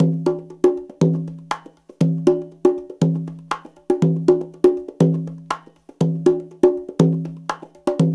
CONGA BEAT20.wav